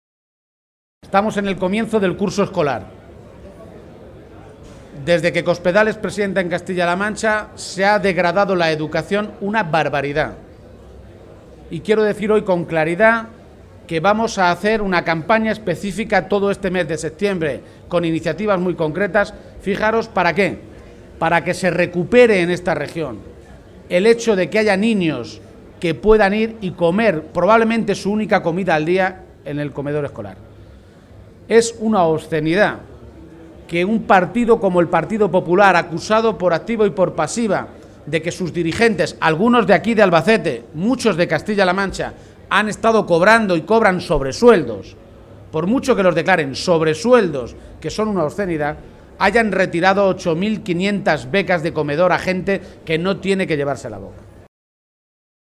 En la atención a medios de comunicación, García Page advirtió que el PSOE de Castilla-La Mancha está dispuesto a seguir el camino marcado por los socialistas en Madrid «para impedir que algo que nos ha costado tanto construir como es la sanidad de la que nos sentimos tan orgullosos se la acaben apropiando unos pocos para hacer negocio».